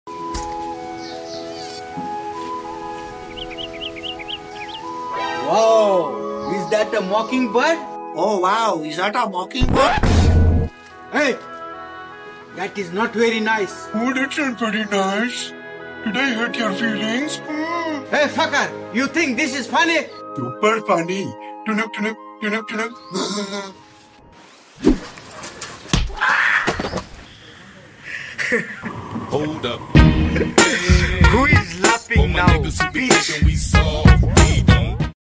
Silly mocking bird sound effects free download